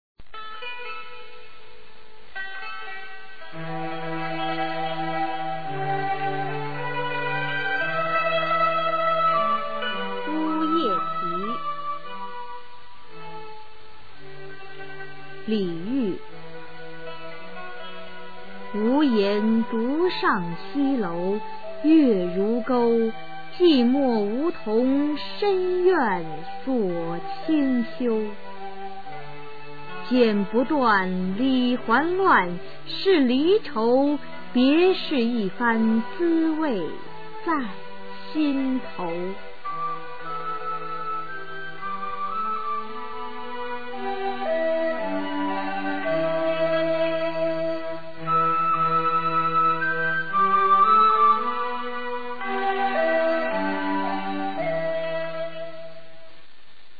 李煜《相见欢》原文和译文（含赏析、朗读）　/ 李煜